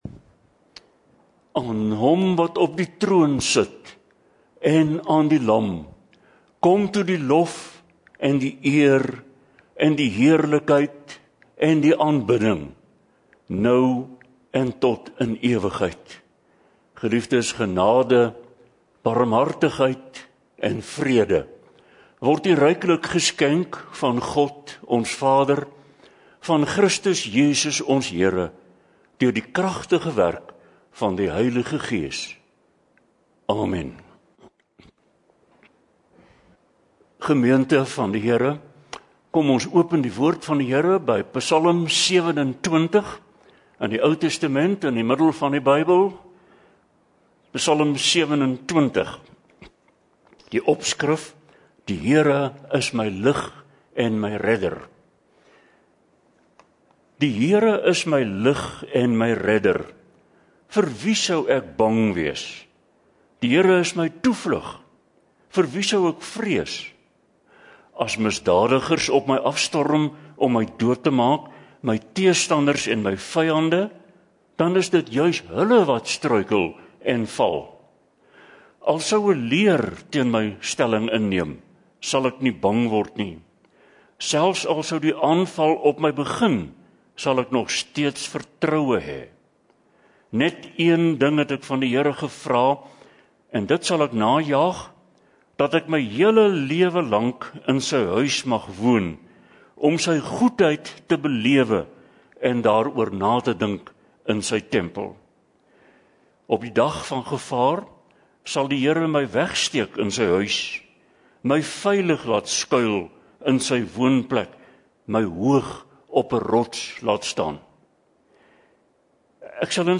Oudiopreke